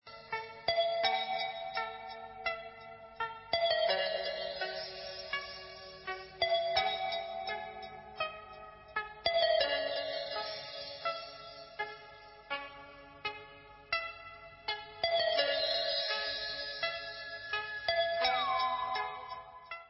sledovat novinky v oddělení Pop/Symphonic